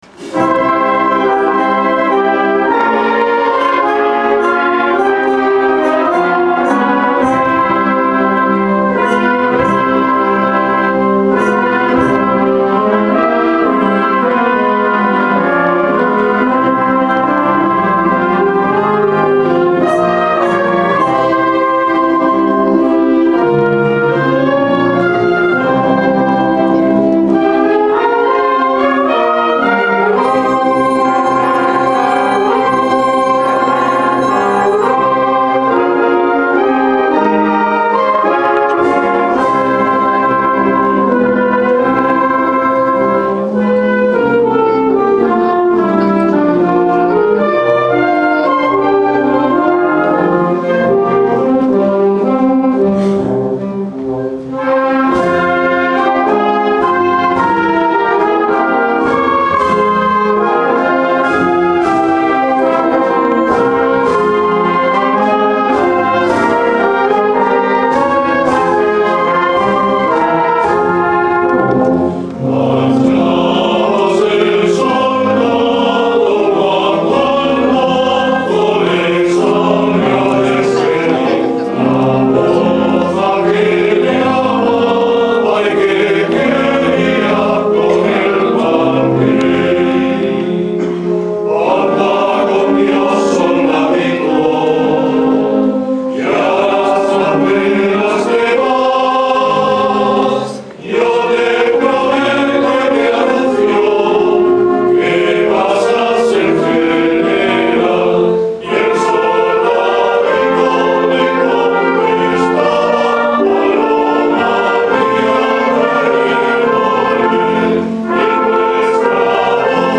Velada Musical Antología de la Zarzuela - Fiestas de Santiago 2016
Agrupación Musical de Totana y Coral Santiago.
zarzuela